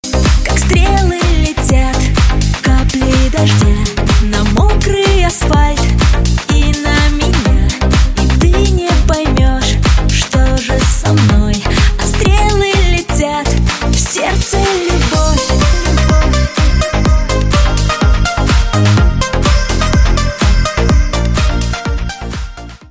поп
женский вокал
dance
club